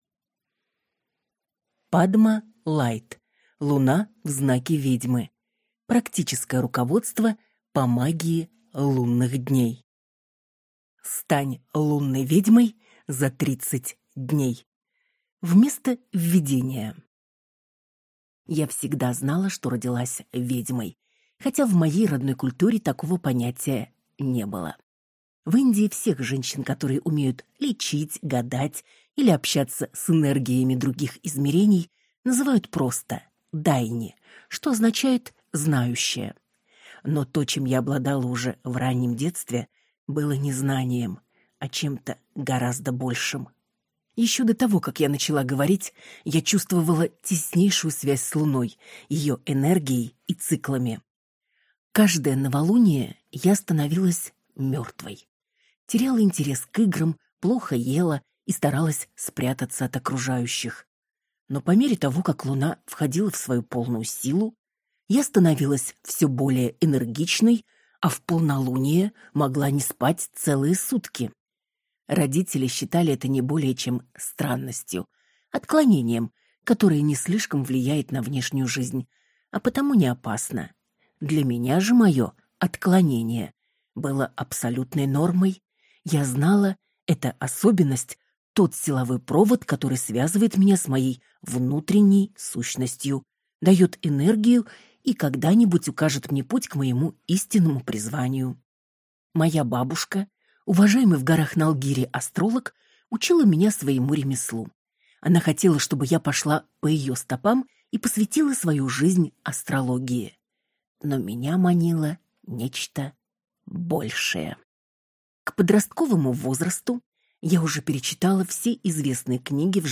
Аудиокнига Луна в знаке ведьмы. Практическое руководство по магии лунных дней | Библиотека аудиокниг